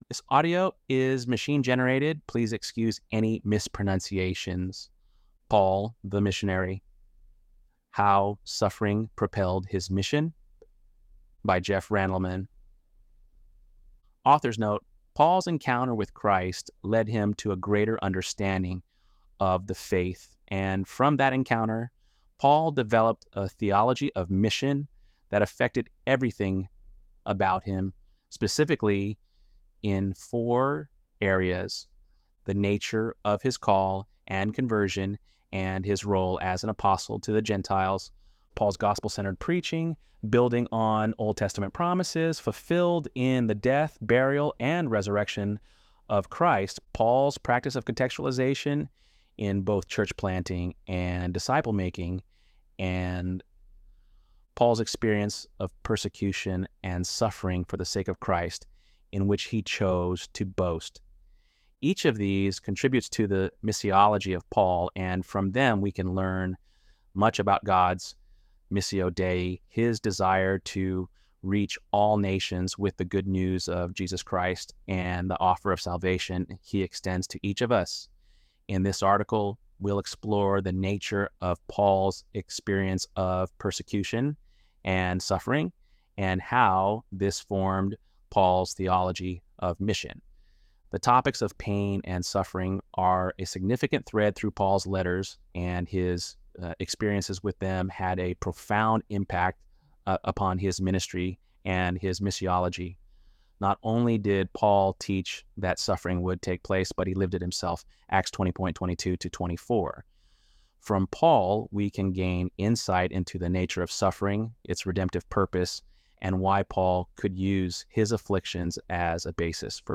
ElevenLabs_8.12.mp3